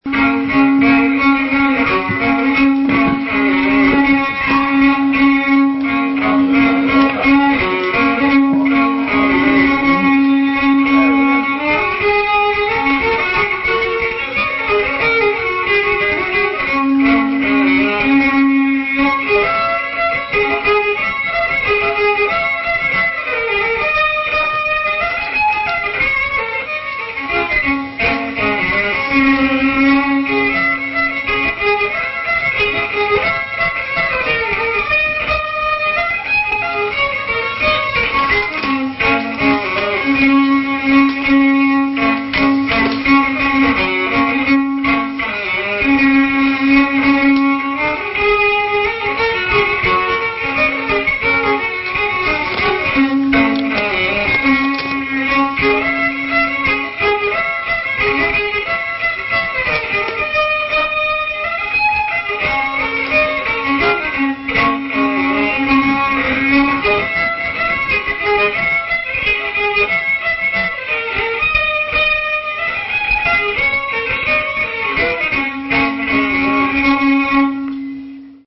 NEW TUNES: KEY OF D